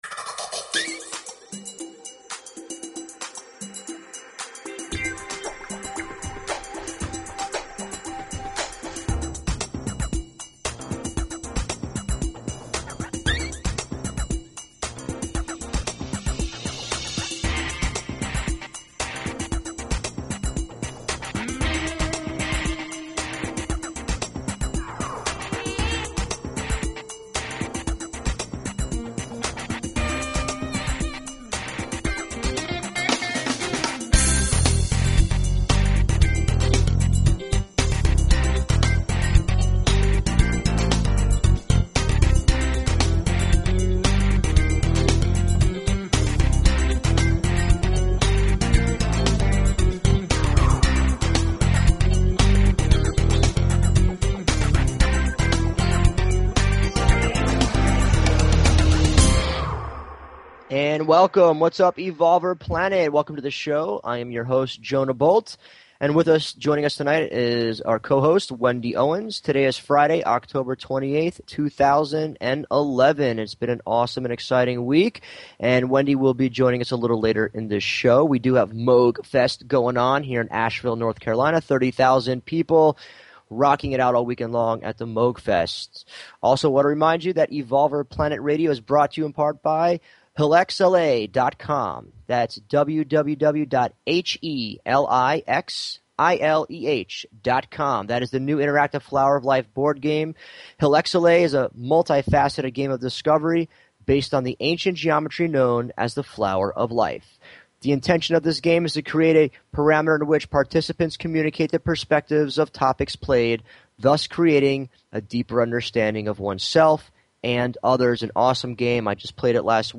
Talk Show Episode, Audio Podcast, Evolver_Planet_Radio and Courtesy of BBS Radio on , show guests , about , categorized as